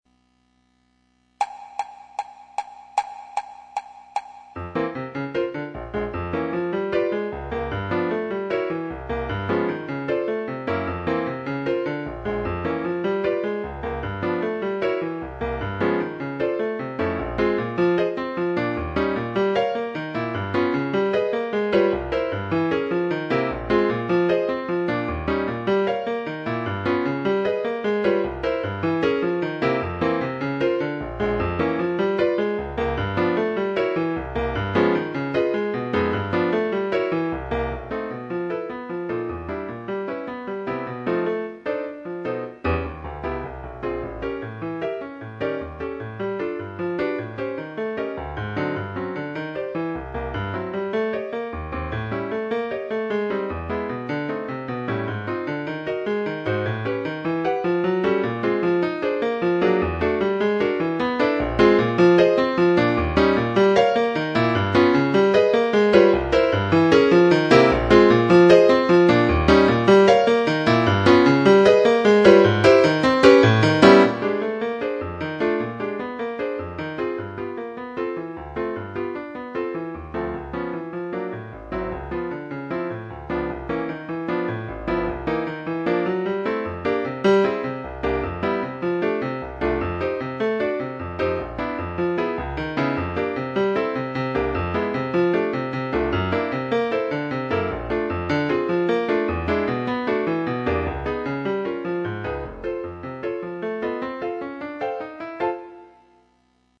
Mambo Piano part